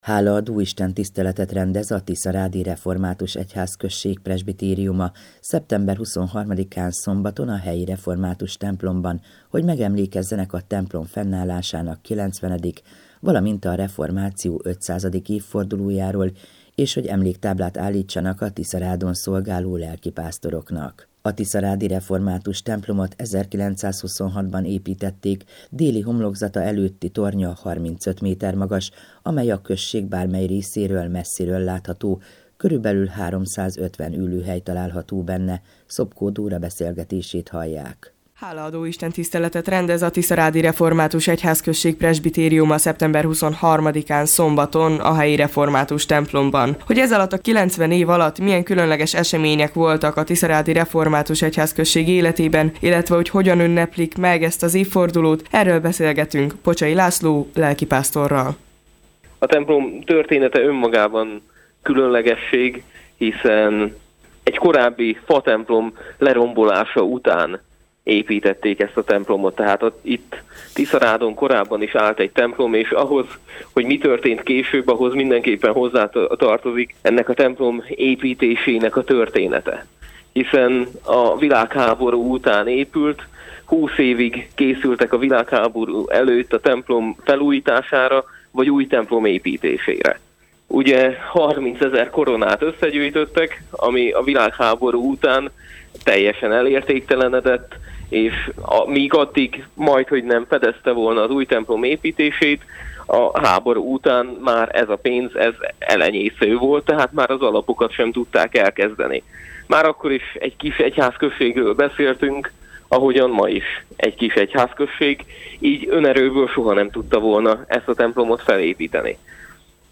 Hálaadó istentisztelet a Tiszarádi Református Egyházközségben - hanganyaggal
Az ünnepi alkalmon az Igét dr. Fekete Károly hirdette a Jn 3,16-21 alapján.